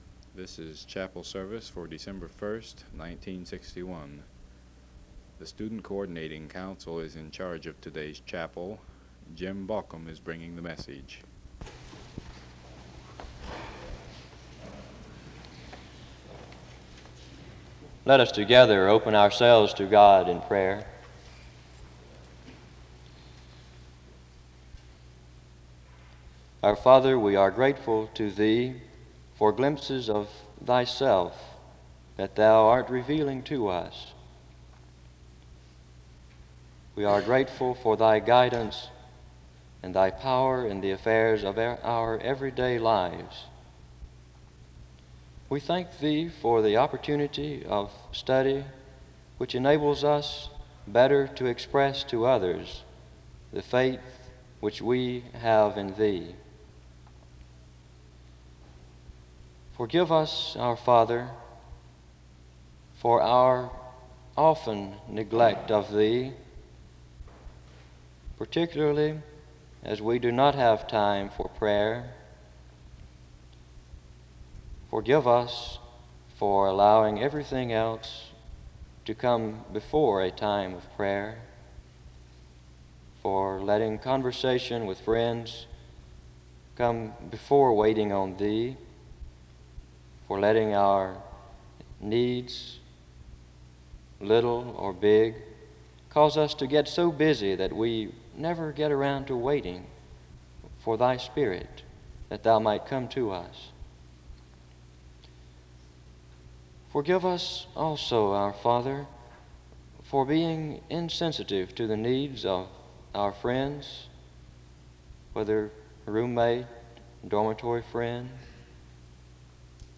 Download .mp3 Description This service was organized by the Student Coordinating Council, and the service begins with prayer (00:00-03:16).